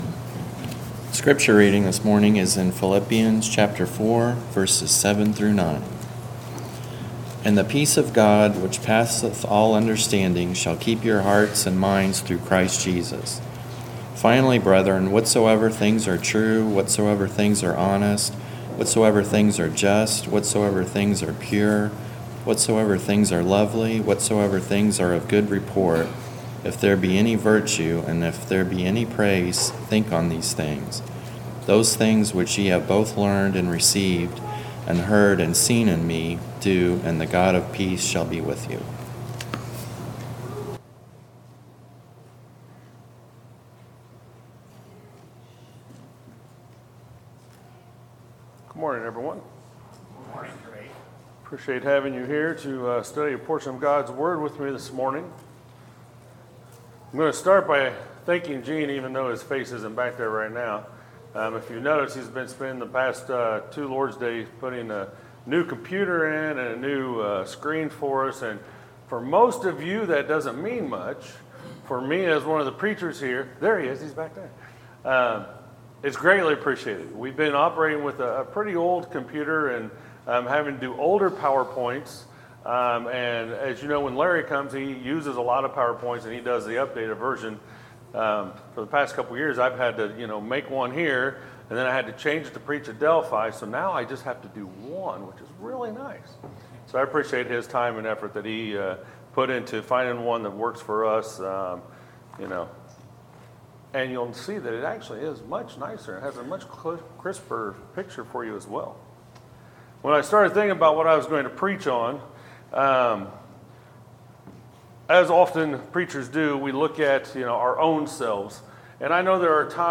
Sermons, August 20, 2017 – Gadsden Church of Christ